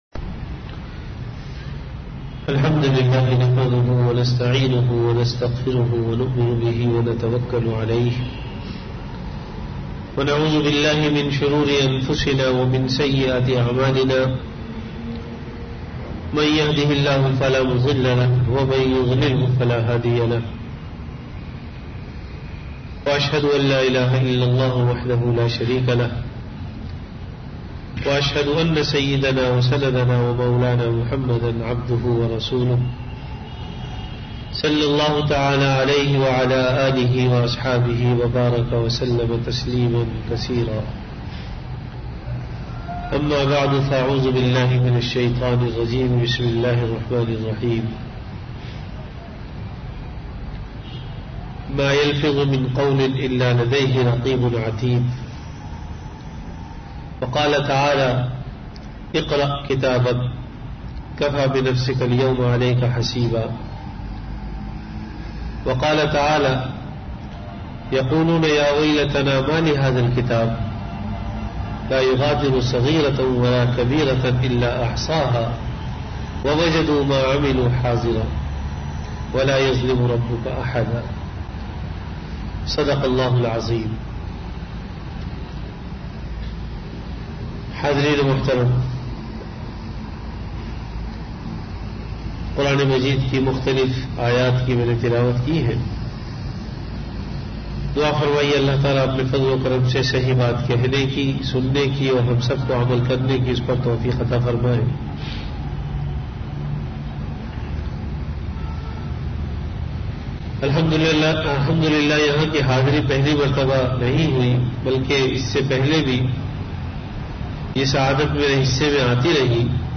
Bayanat · Ashraf Community Centre, Karachi